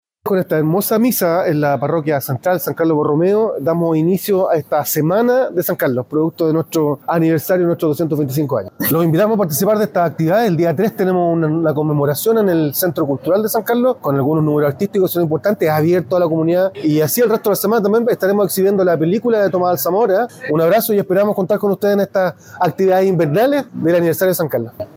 Ruben-Mendez-alcalde.mp3